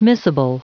Prononciation du mot miscible en anglais (fichier audio)
Prononciation du mot : miscible